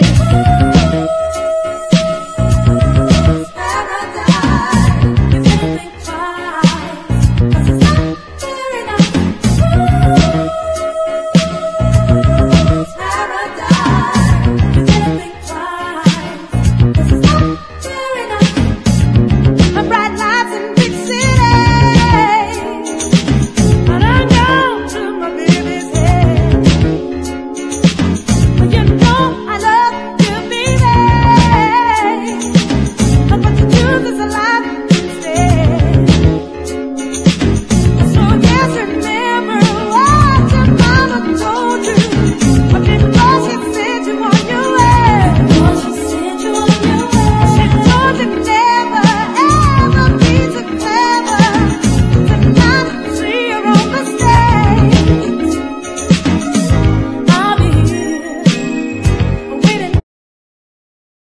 80年の異色シンセ・ディスコ！